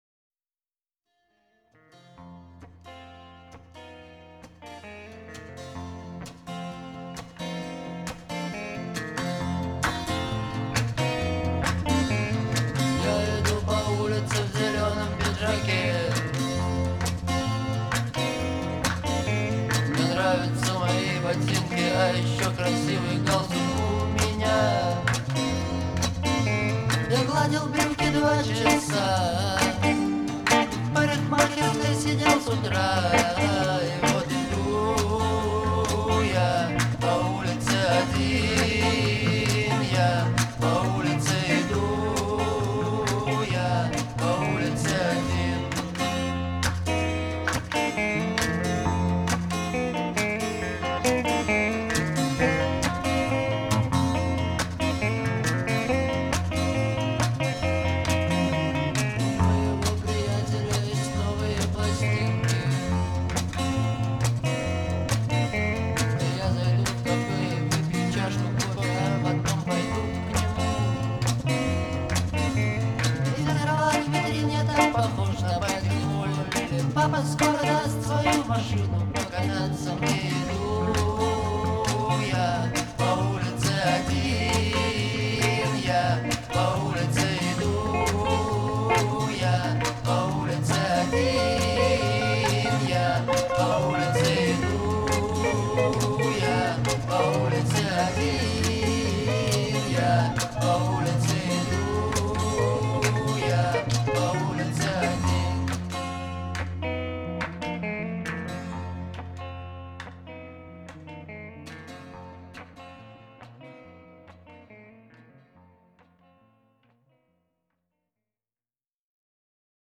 мощные гитары, запоминающаяся мелодия